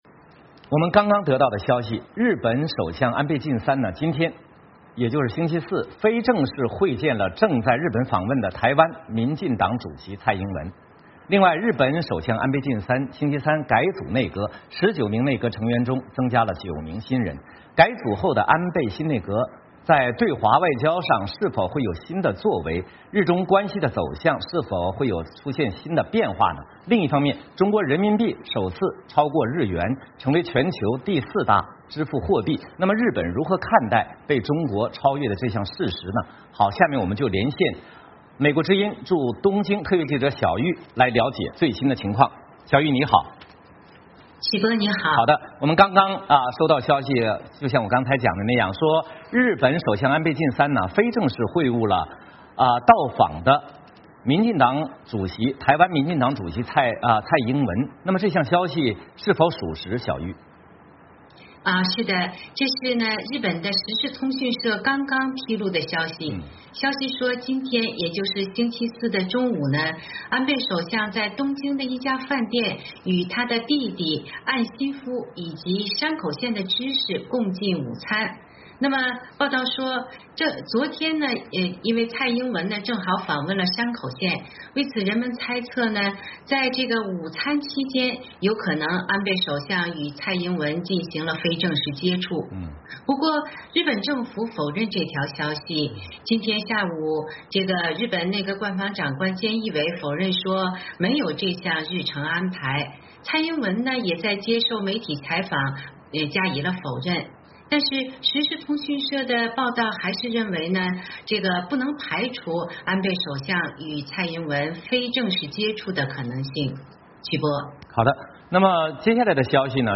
VOA连线:日本内阁改组对中日关系有何影响